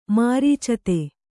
♪ mārīcate